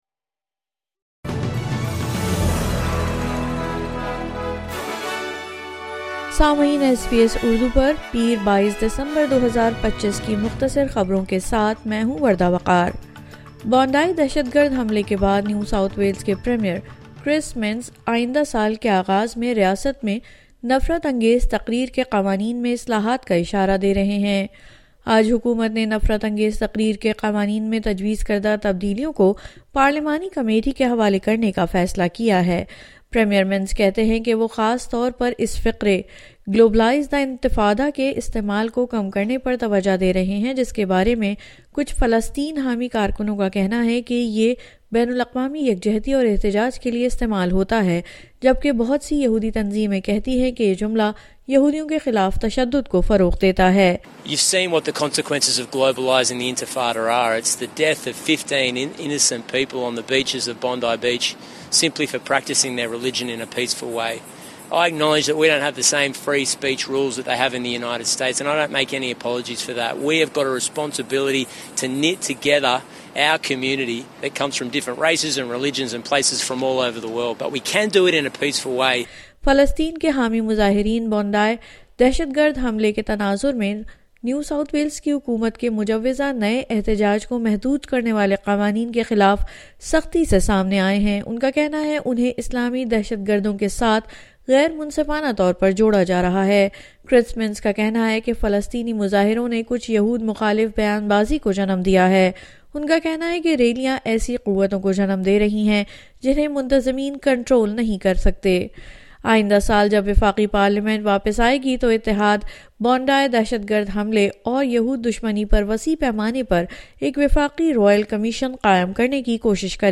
مختصر خبریں : پیر 22 دسمبر 2025